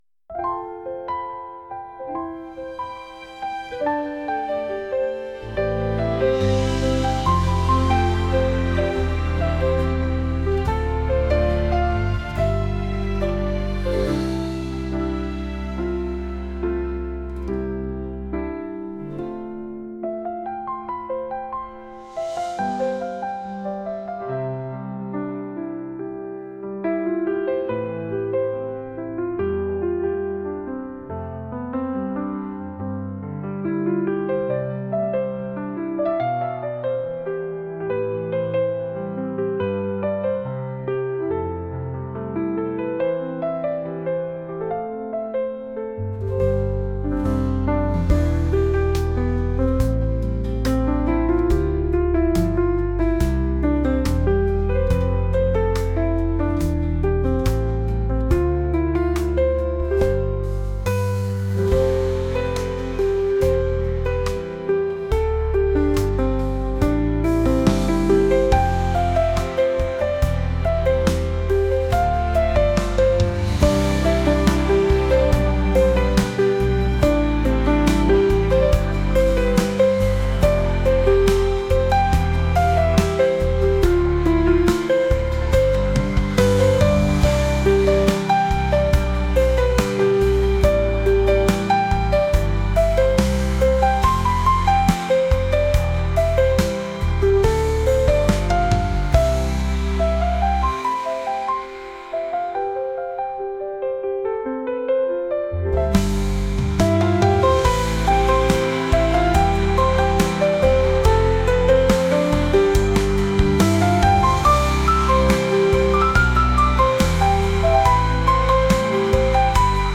幸せな記憶に浸っているようなのピアノ曲です。